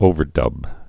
(ōvər-dŭb)